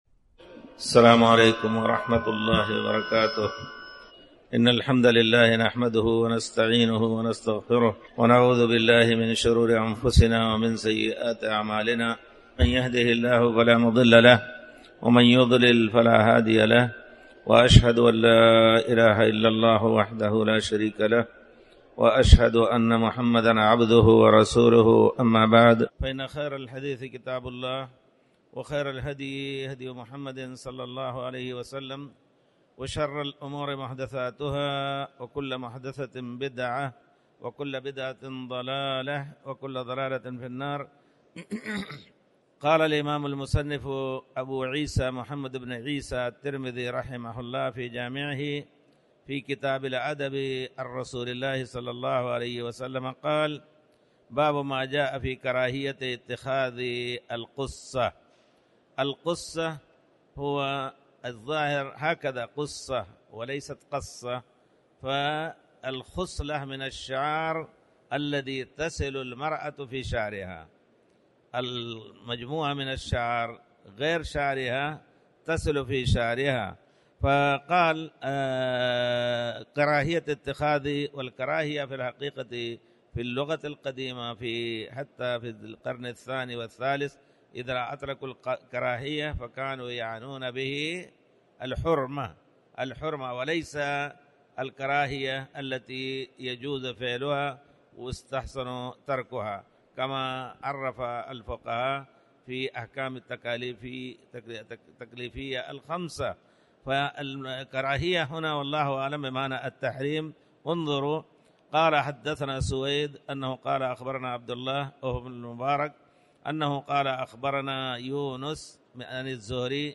تاريخ النشر ٨ رمضان ١٤٣٩ هـ المكان: المسجد الحرام الشيخ